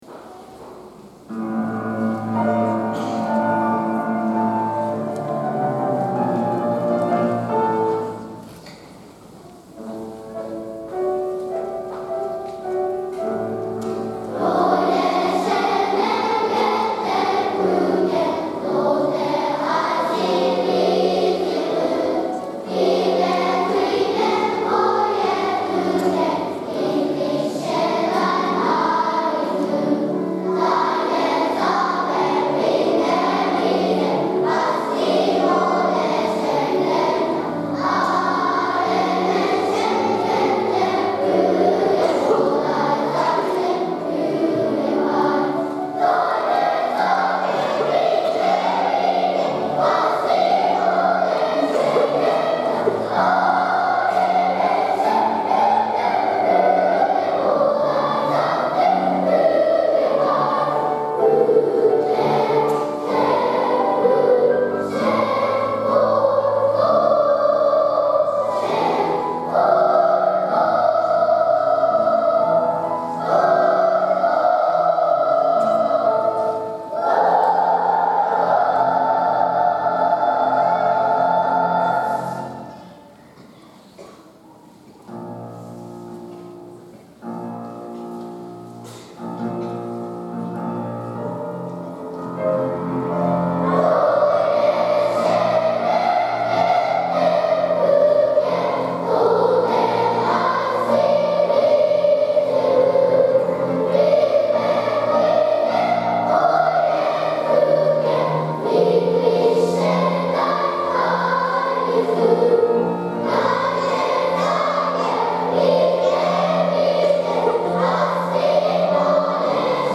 大空ありがとうコンサート
よろこびの歌」１・２・３年２部合唱です。
１・２・３・年生は、ドイツ語で歌うことにチャレンジしました！
初めはなかなか天使の声で歌うことが難しかったですが、歌の意味を学んだあとは、透き通った天使の声で歌えるようになりました♪
みんなが平和になるようにという思いを込めながら、ソプラノとアルトのハーモニーを響かせ、会場中にありがとうの気持ちを伝えることができました。